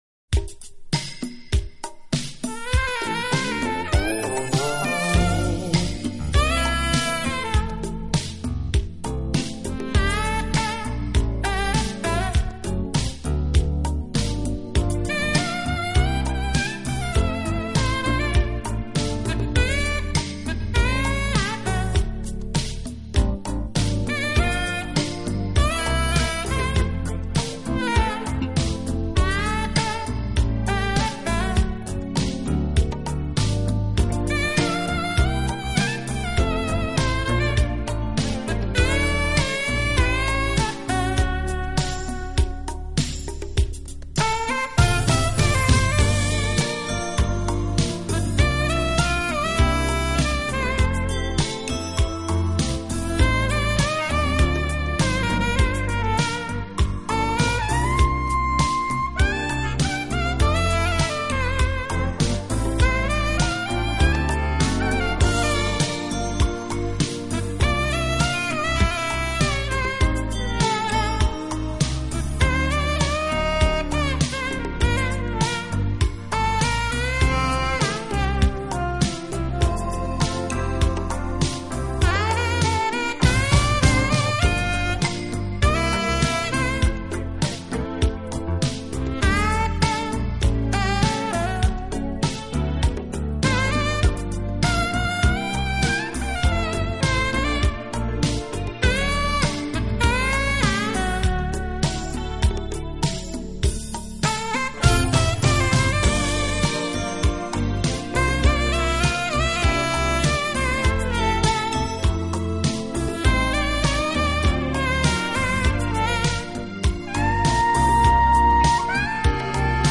Funk Jazz
jazz-funk
tenor sax
trumpet
keyboards